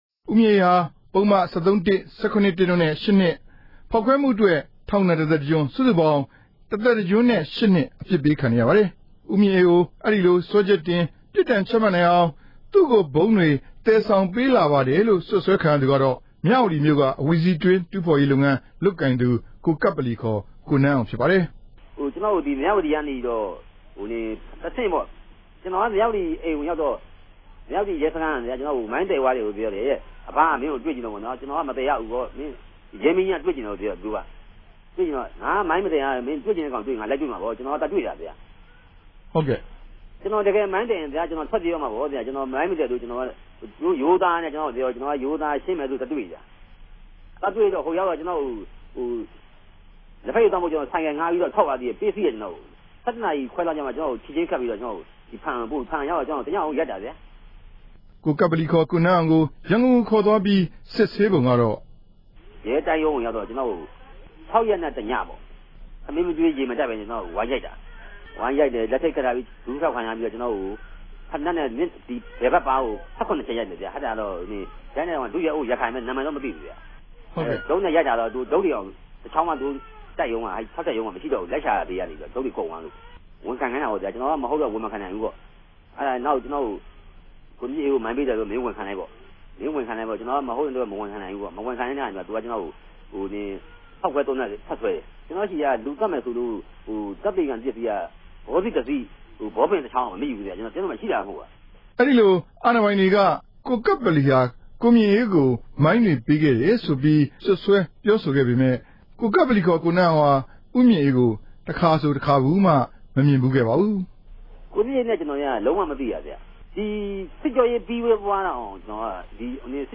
မေးမြန်းတင်ပြချက်။